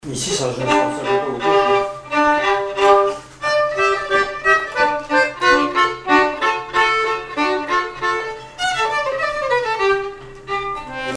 Localisation Miquelon-Langlade
Répertoire de bal au violon et accordéon
Pièce musicale inédite